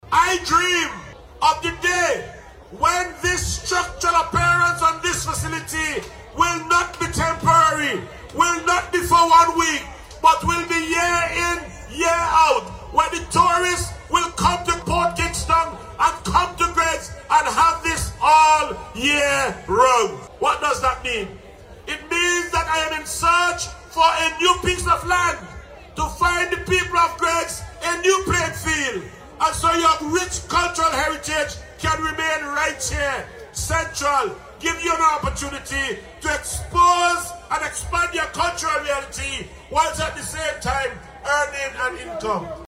Minister Bruce gave this assurance during his remarks at the Greiggs National Heroes Day festivities this month.